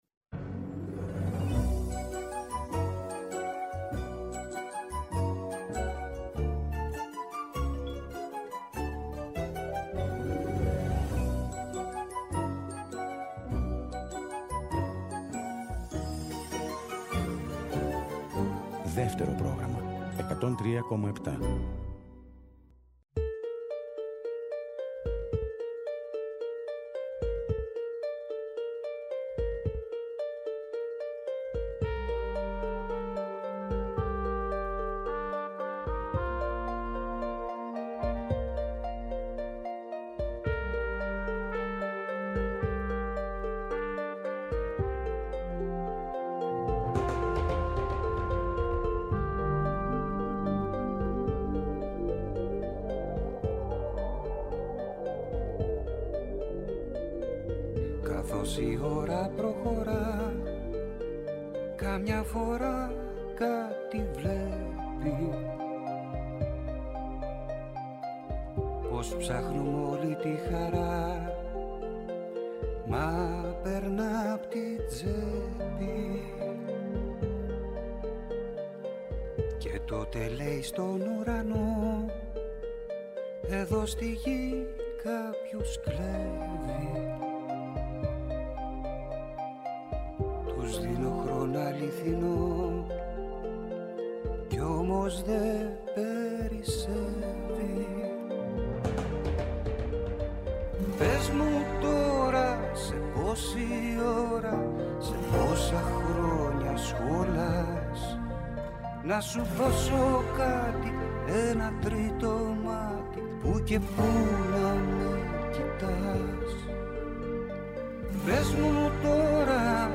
με αποσπάσματα από συνεντεύξεις του καλλιτέχνη